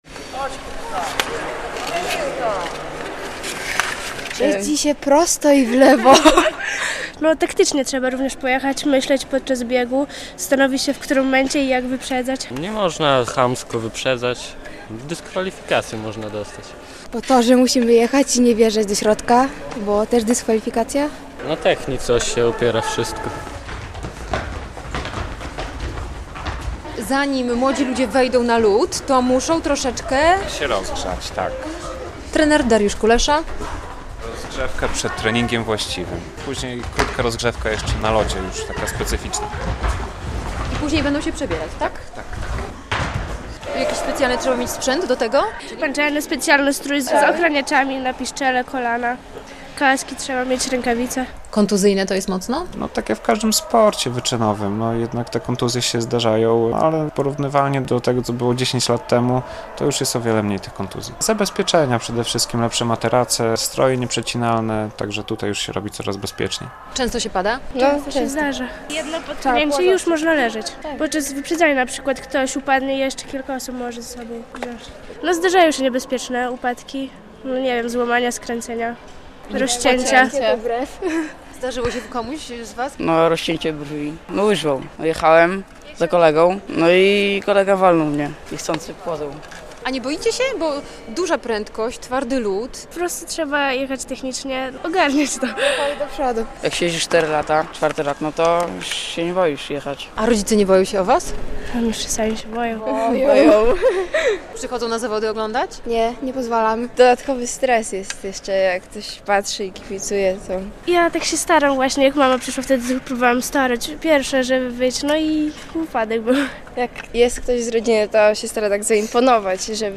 Na lodowisku białostockiego MOSiR-u młodzież trenuje short track, czyli łyżwiarstwo szybkie.